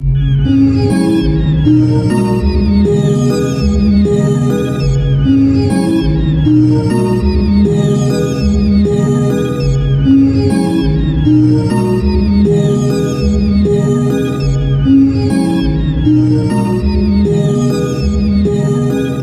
Tag: 100 bpm Rap Loops Synth Loops 3.23 MB wav Key : Unknown